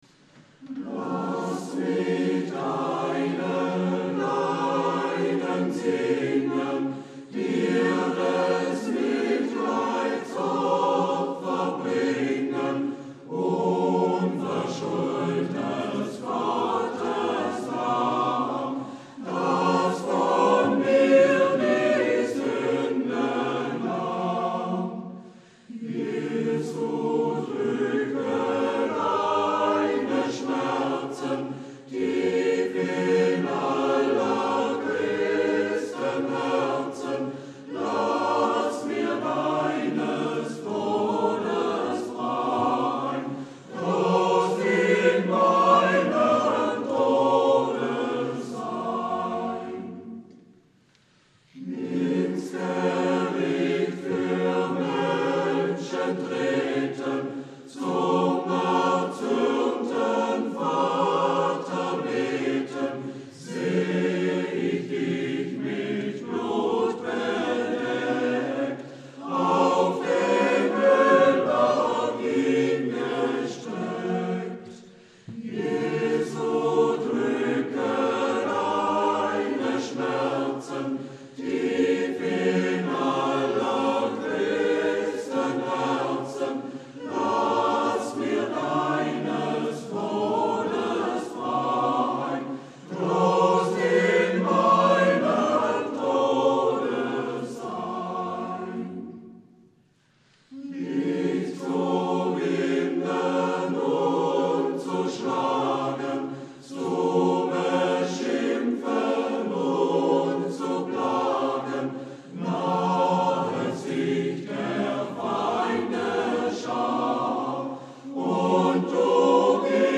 Die Karfreitagsliturgie lässt nicht viel Spielraum für Experimente.